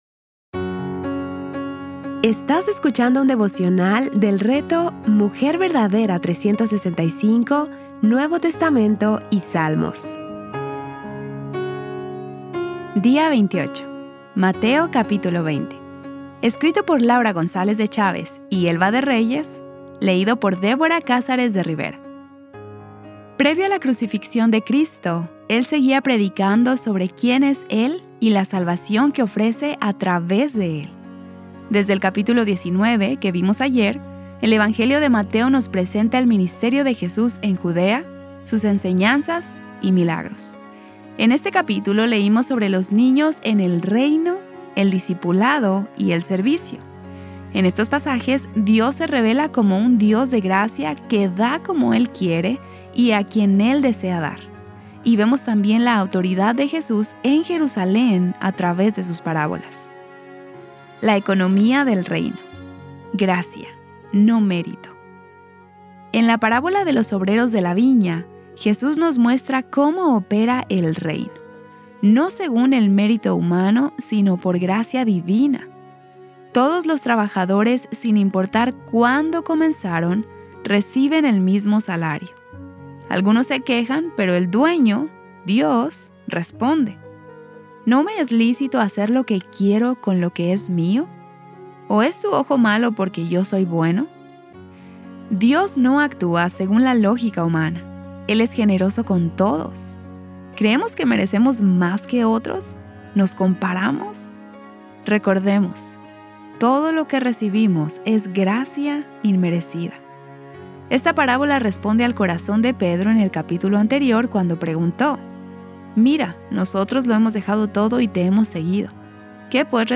Series:  Mateo y Salmos | Temas: Lectura Bíblica